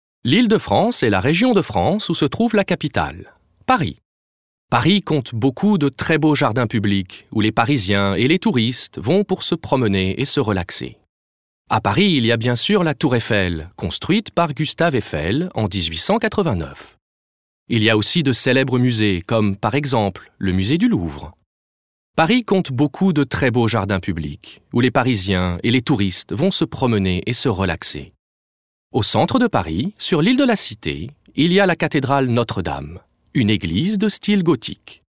Sprecher französisch mit neutralem Pariser Akzent.
Voice over talent french (Parisian neutral accent).